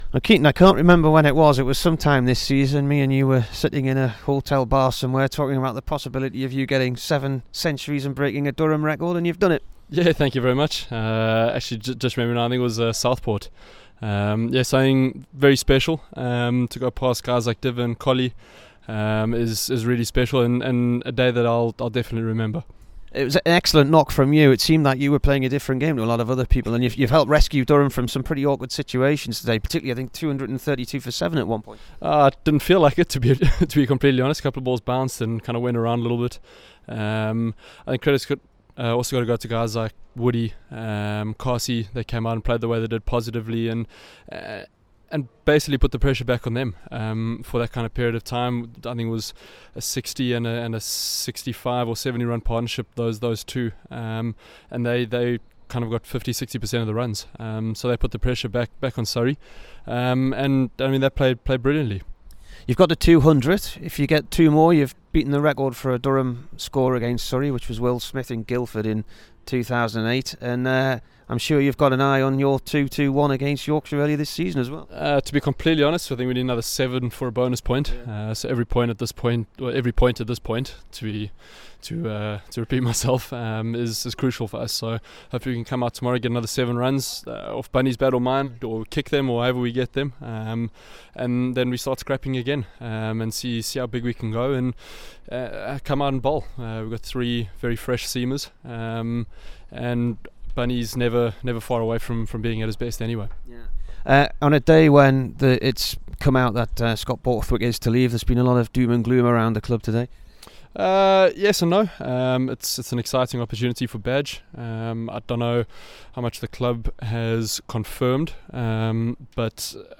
KEATON JENNINGS INT